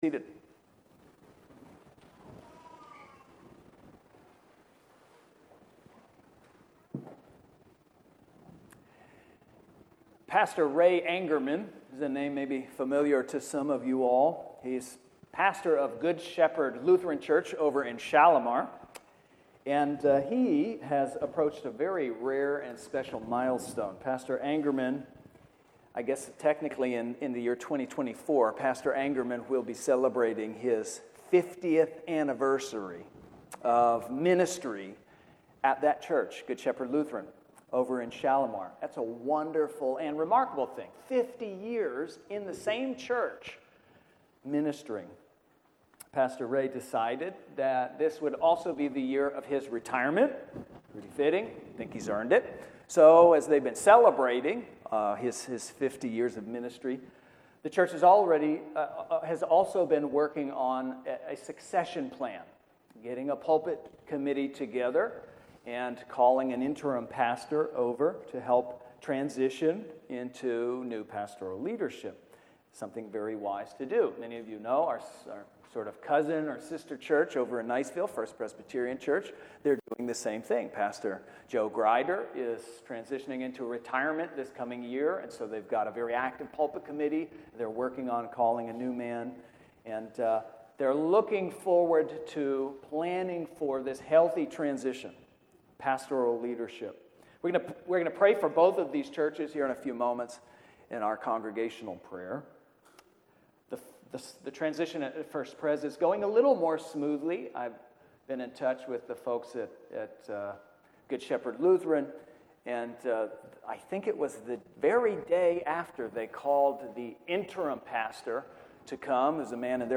Sermons | Trinity Presbyterian Church